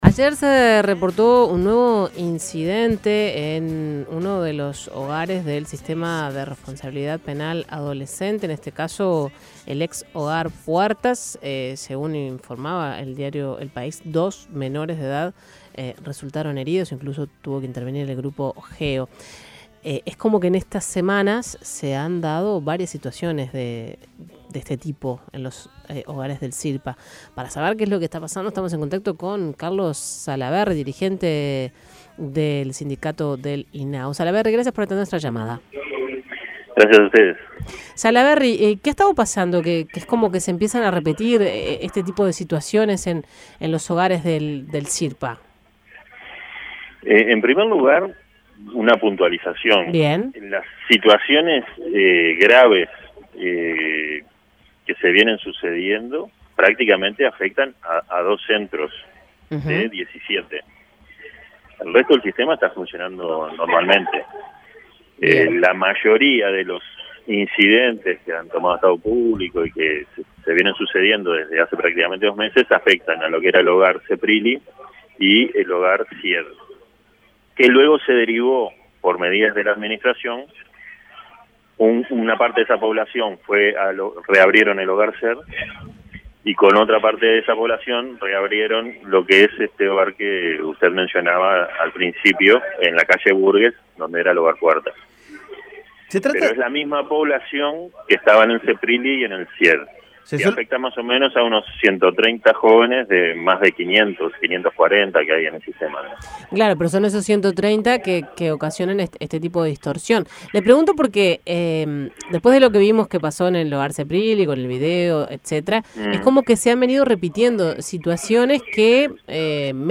Escuche la entrevista en 810 Vivo